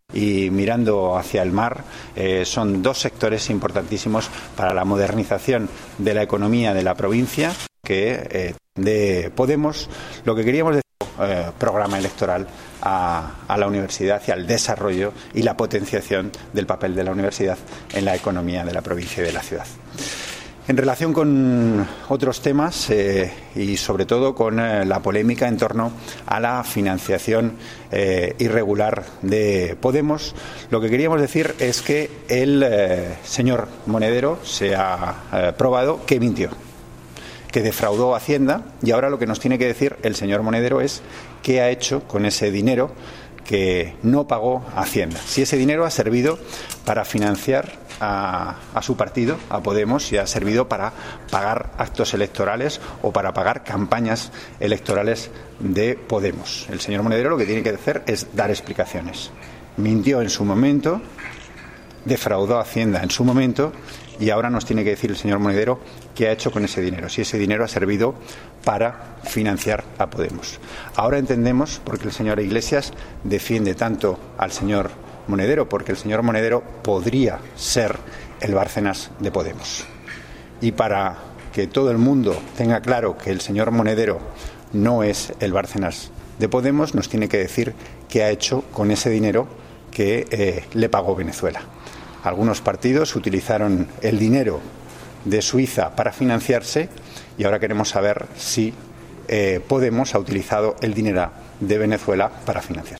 Declaraciones de Antonio Hernando al saberse que Juan Carlos Monedero regularizó su situación con Hacienda tras publicarse el ingreso de casi 500.000 euros en su empresa